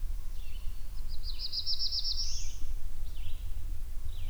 hill I heard this song:
bird remained high but moved around quite a bit (well, the song did, as I never
itself to be "just" a black-throated blue warbler.